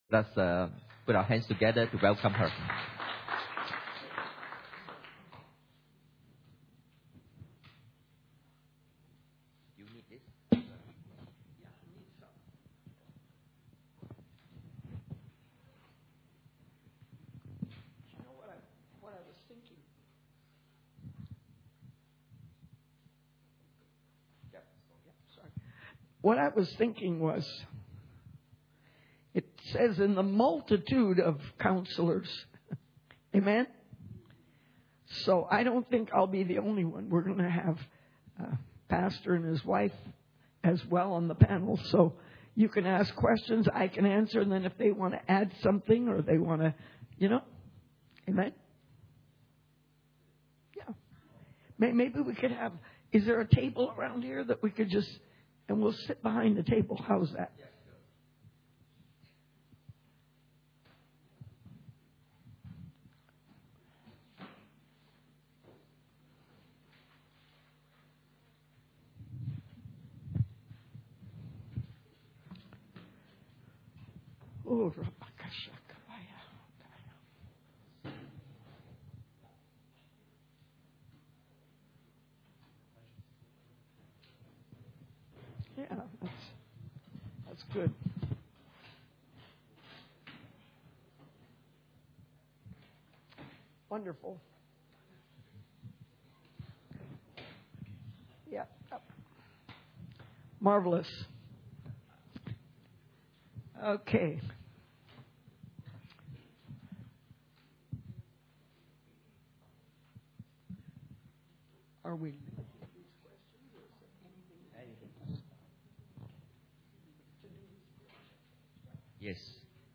Church Camp 2012 Session 7 – Questions and Answers
Church Camp 2012 Session 7 - Questions and Answers.mp3